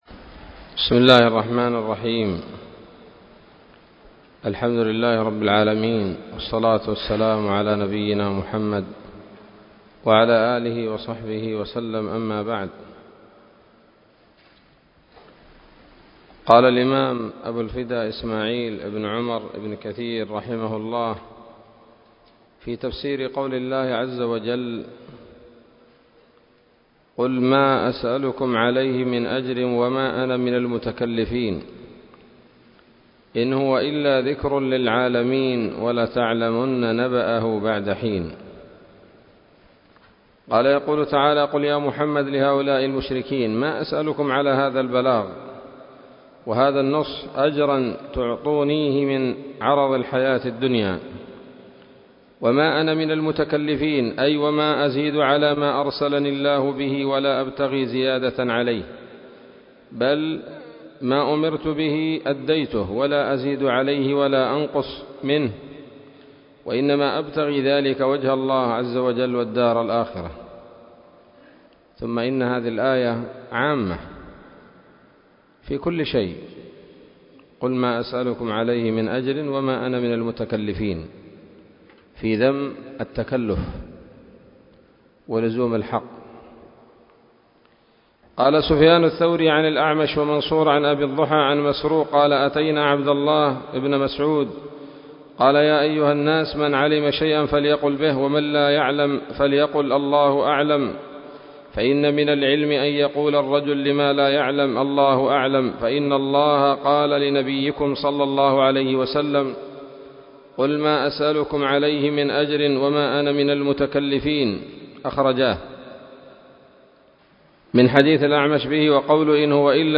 الدرس الثالث عشر وهو الأخير من سورة ص من تفسير ابن كثير رحمه الله تعالى